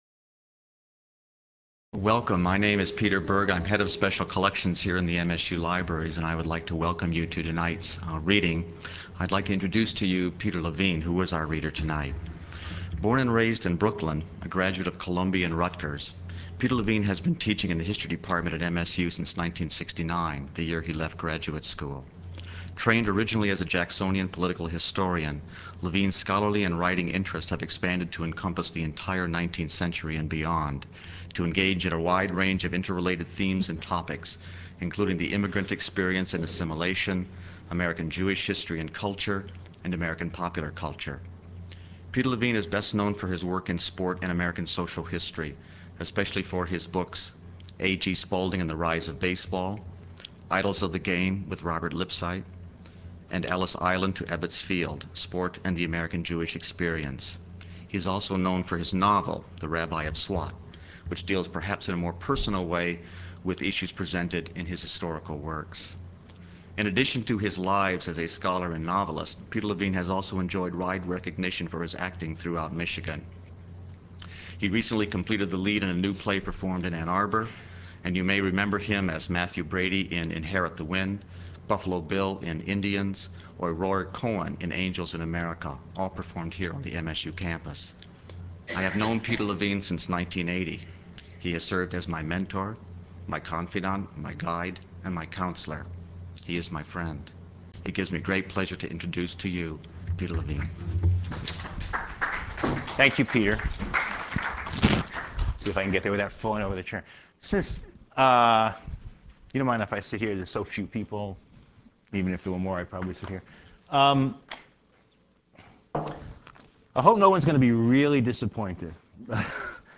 Recorded at the Michigan State University Libraries by the Vincent Voice Library on Jan. 28, 2000.